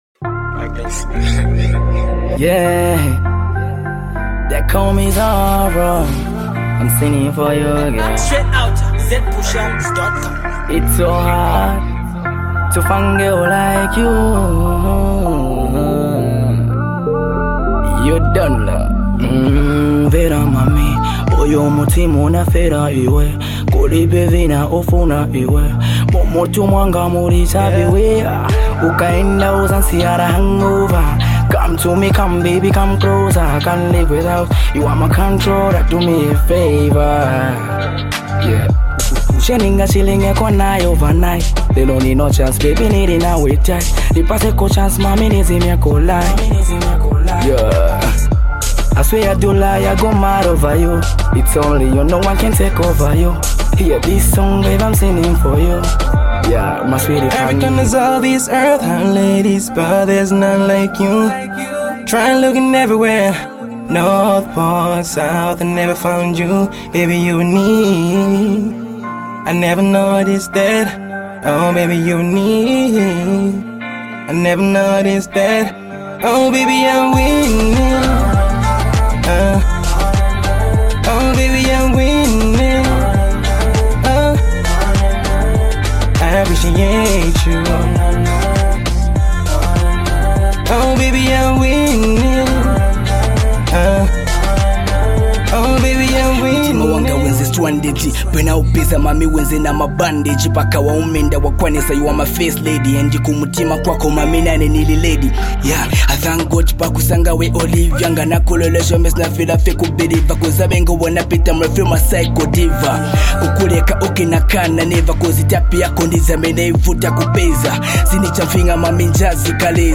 get to vibe to some great RnB awesome record below.